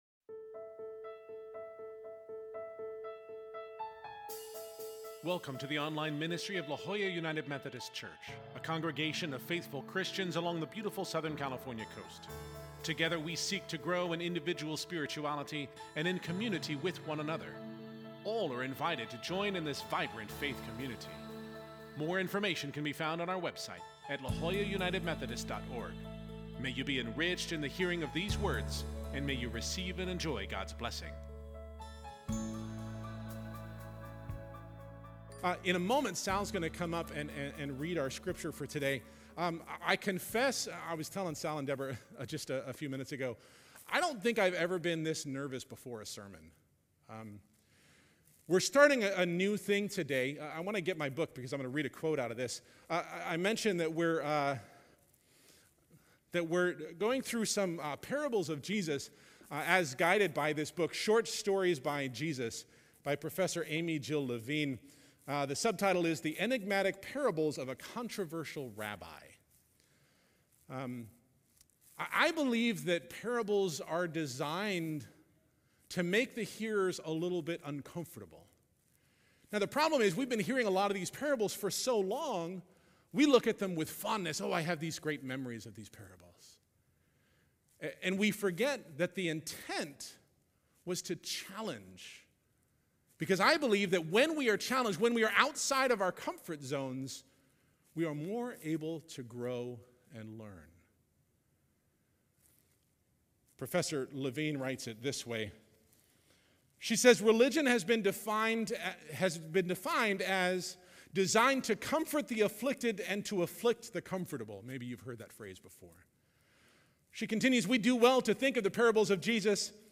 This Sunday we begin a new sermon series on the parables of Jesus, and the first will be the familiar story of the prodigal son.